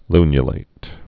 (lnyə-lāt, -lĭt) also lu·nu·lat·ed (-lātĭd)